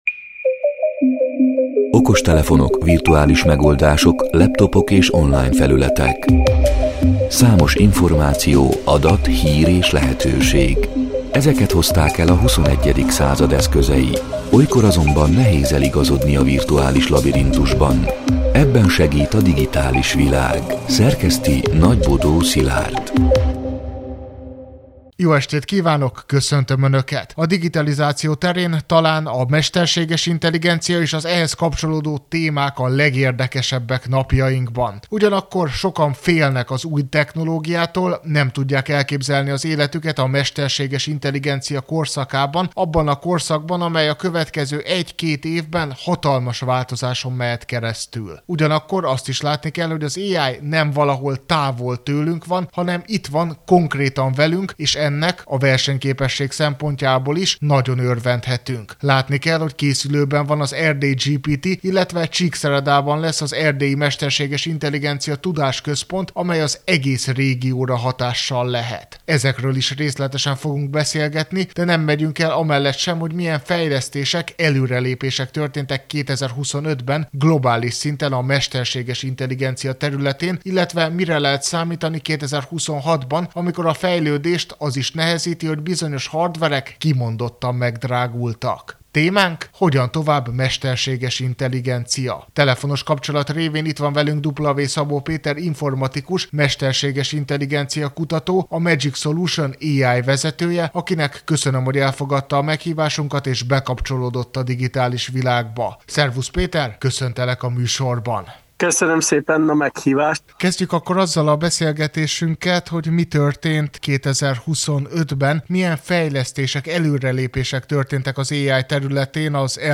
A Marosvásárhelyi Rádió Digitális Világ (elhangzott: 2026. január 20-én, kedden este nyolc órától) c. műsorának hanganyaga: